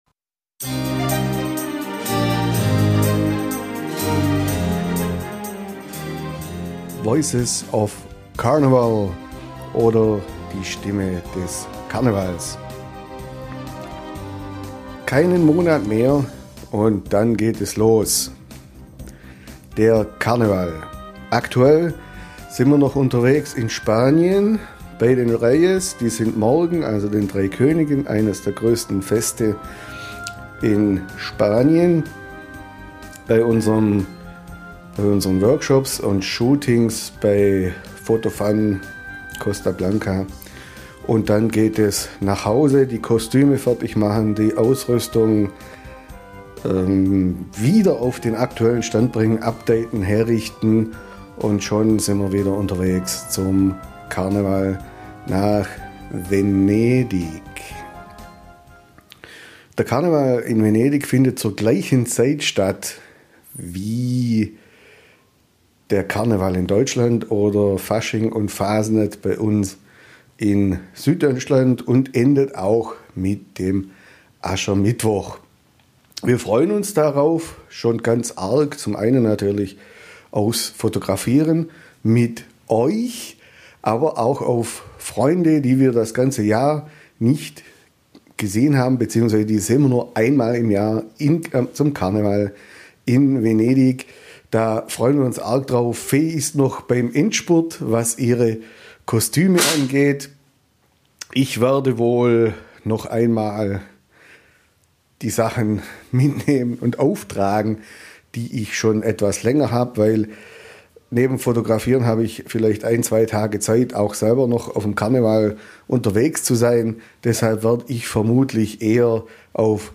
Wir haben Euch mal ein paar Statements von Karnevalbegeisterten gesammelt, vielleicht findet ihr euch dabei ja wieder Mehr